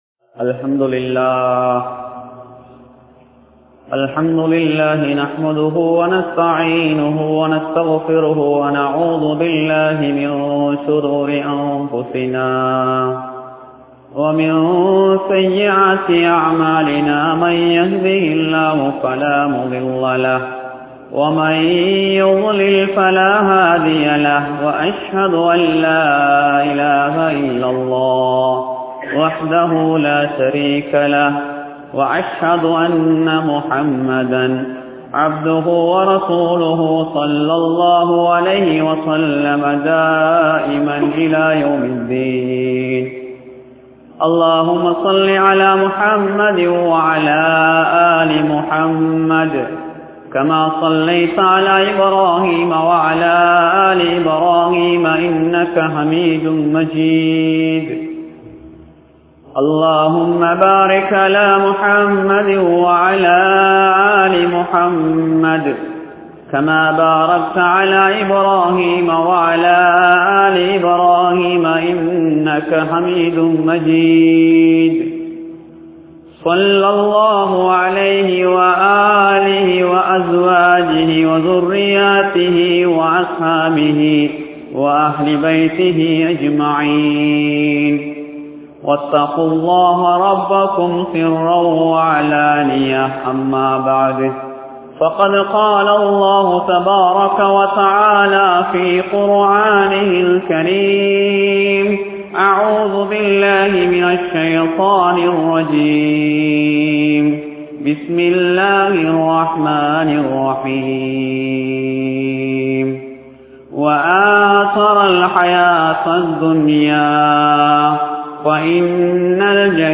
Thaqwavin Avasiyam (தக்வாவின் அவசியம்) | Audio Bayans | All Ceylon Muslim Youth Community | Addalaichenai
Negombo, Kamachoda Jumua Masjith